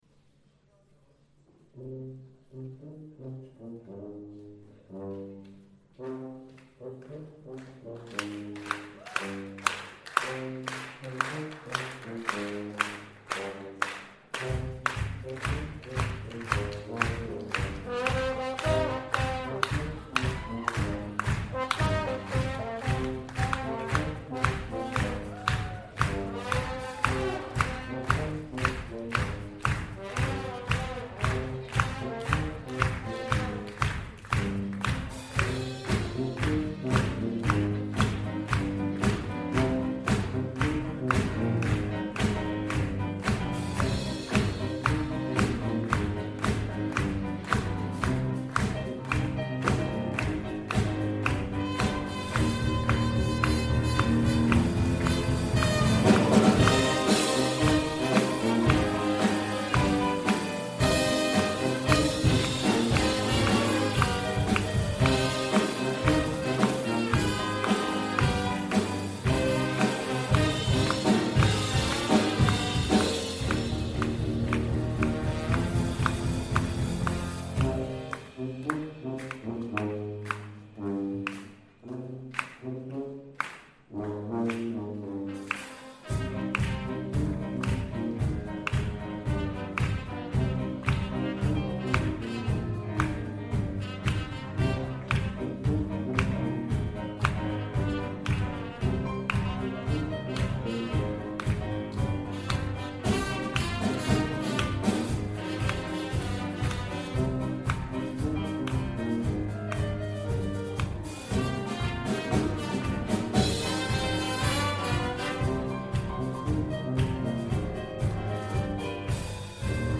Trad Band